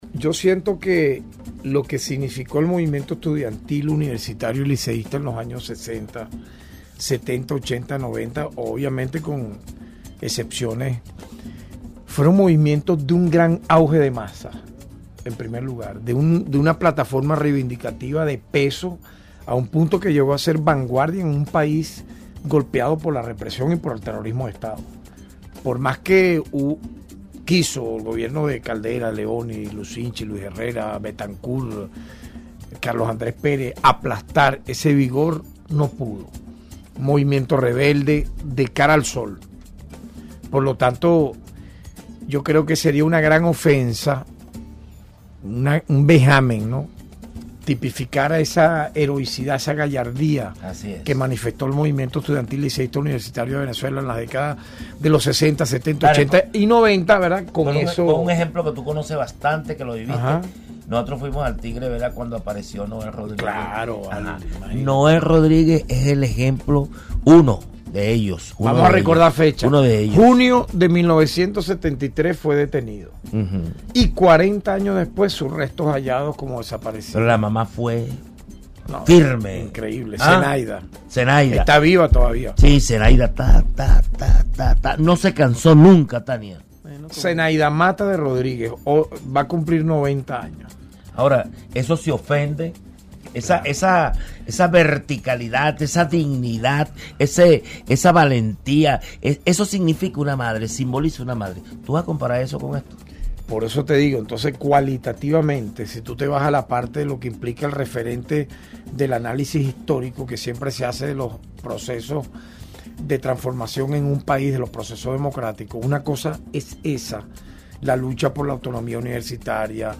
Defensor del Pueblo en Radio Nacional de Venezuela habla de “ONG´s de maletin”